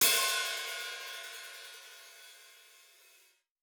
TC2 Live Hihat5.wav